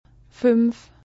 [ɱ] stimmhafter labio-dentaler Nasal